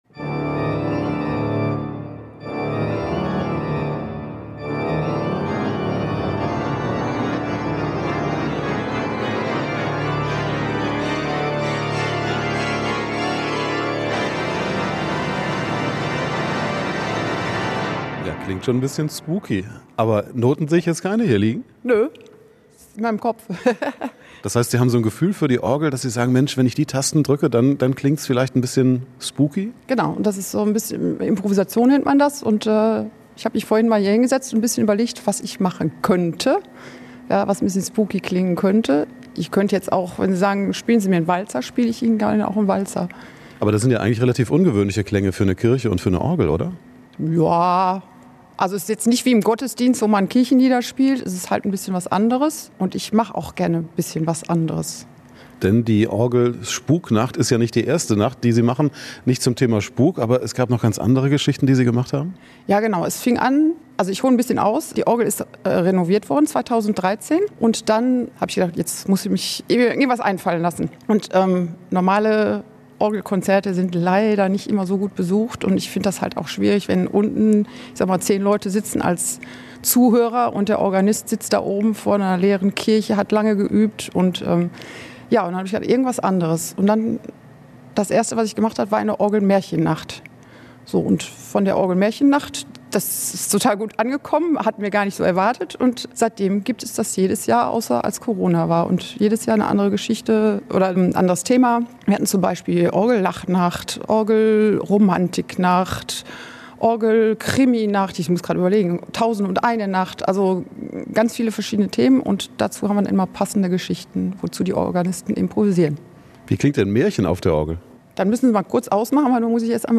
Bunte Scheinwerfer in der ganzen Kirche, dazu spooky Bilder an der Decke, schaurige Texte und die passende Musik von der Orgel.
Gruselige Atmosphäre in der Kirche.